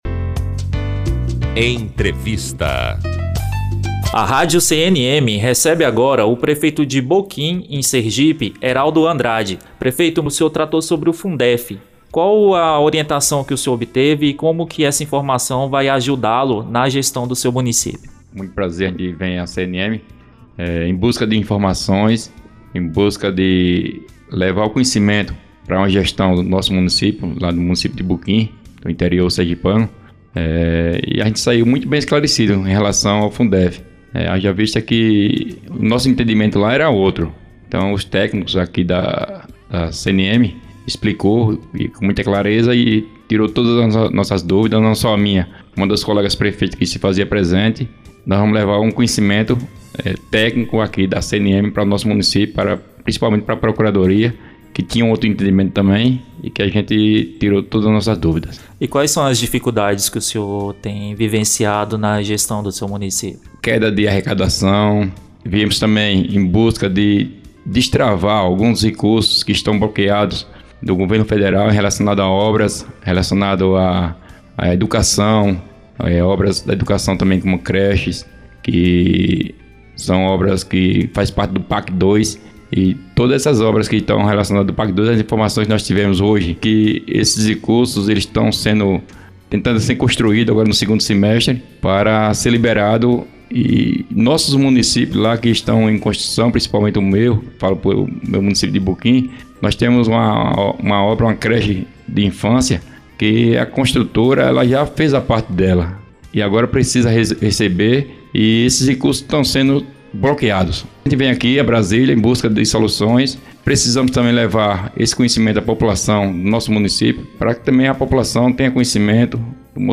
Entrevista - Prefeito Eraldo Dantas - Boquim(SE)
Entrevista---Prefeito-EraldoDantas---BoquimSE.mp3